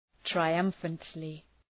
Προφορά
{traı’ʌmfəntlı} (Επίρρημα) ● θριαμβευτικώς